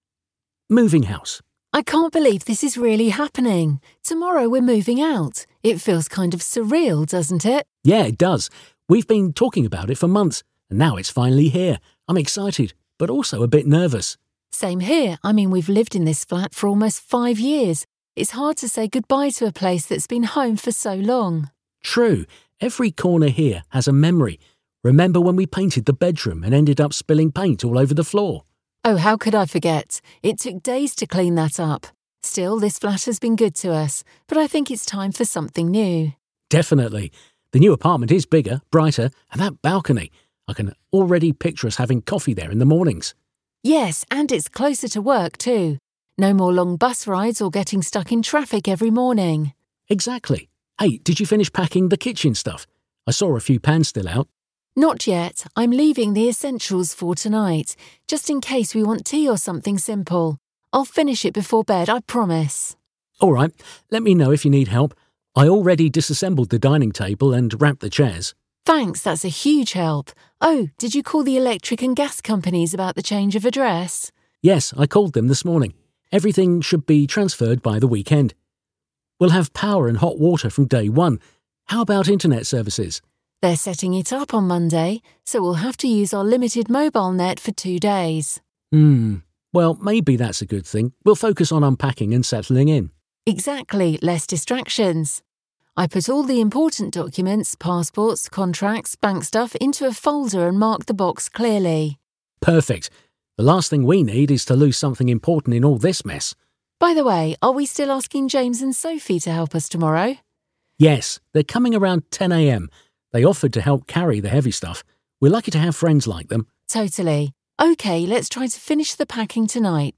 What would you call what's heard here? Hasznos angol párbeszéd: Költözködés